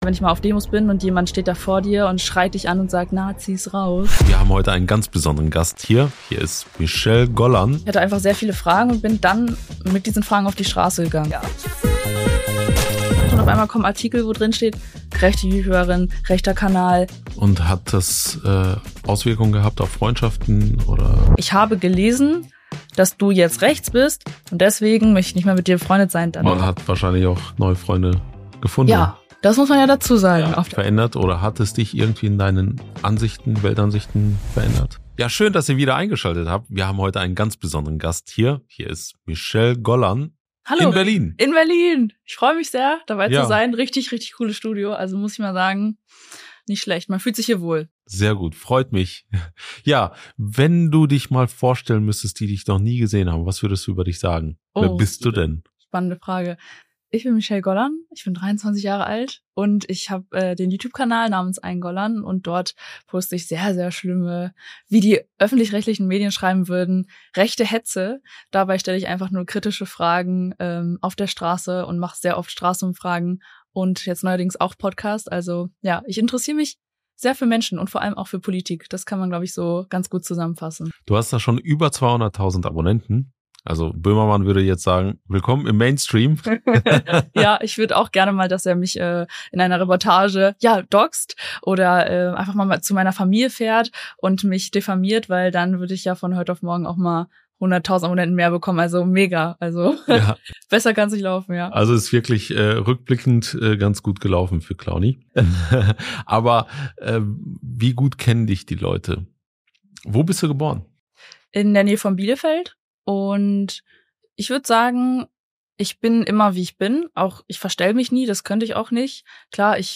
Junge Freiheit Interview »Warum polarisierst du